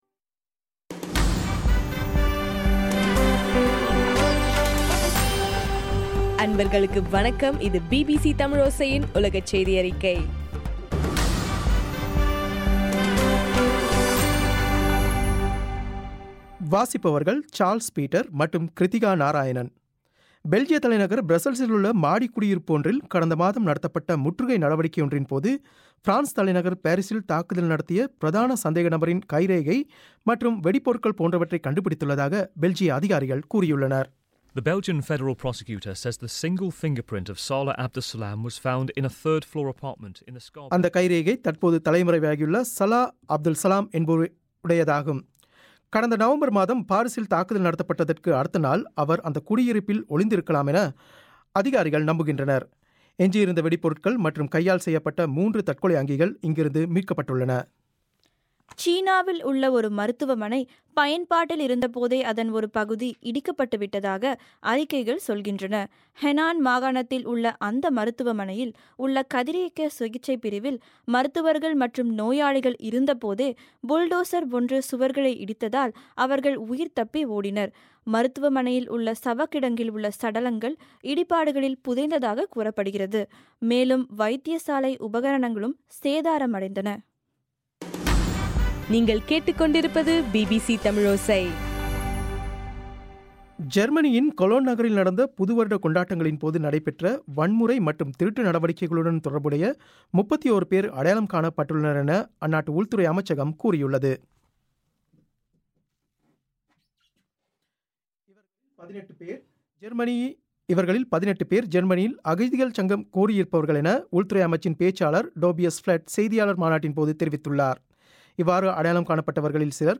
ஜனவரி 8, 2016 பிபிசி தமிழோசையின் உலகச் செய்திகள்